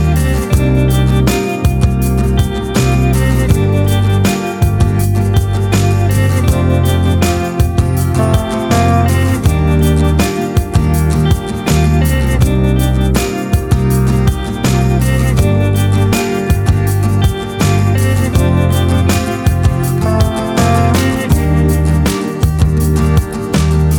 no Backing Vocals Indie / Alternative 4:30 Buy £1.50